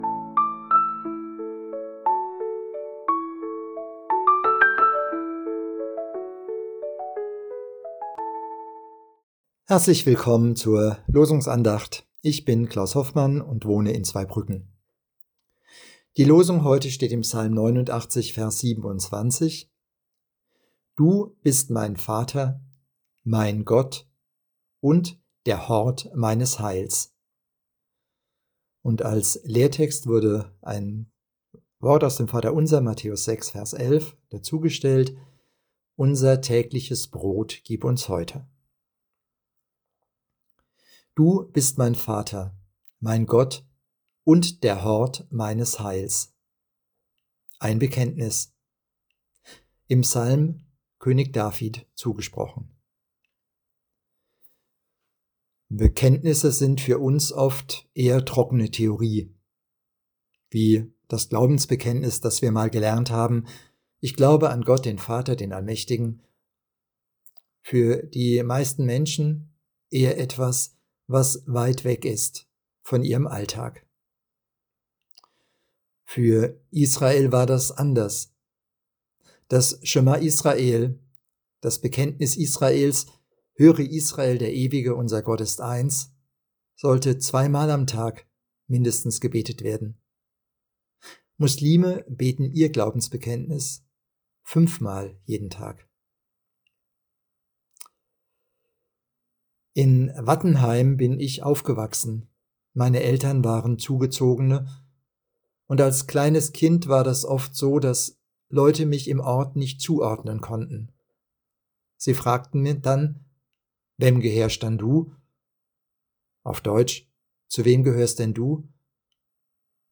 Losungsandacht für Mittwoch, 04.06.2025